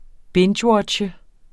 Udtale [ ˈbendɕˌwʌdɕə ]